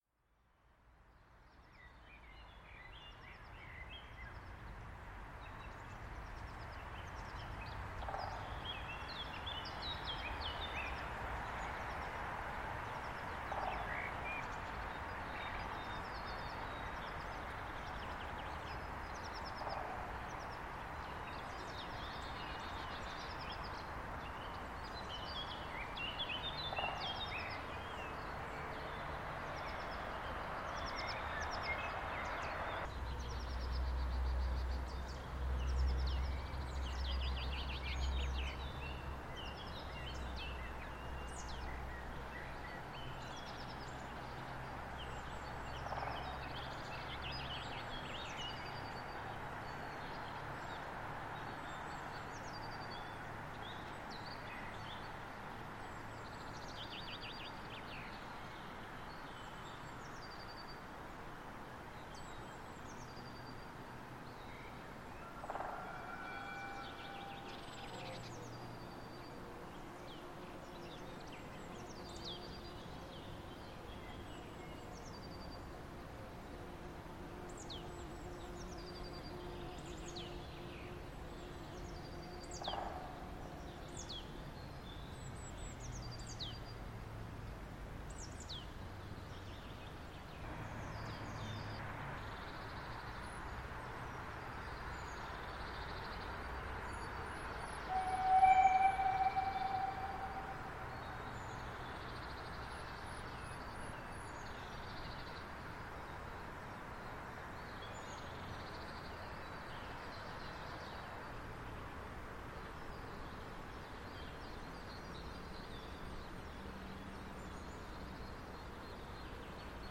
Nun wieder die Brigadelok 99 3317  mit dem dritten Zug von Bad Muskau nach Weißwasser, etwa 50m weiter unten aufgenommen, in der Kurve mit stärkster Steigung über die Waldwiese (Außenseite), um 13:52h am 18.04.2025.
Deutlich auch zu hören, wie der Zug exakt in dieser Kurve wohl in den kurzen, steilsten Streckenabschnitt der gesamten Bahn hineinfährt.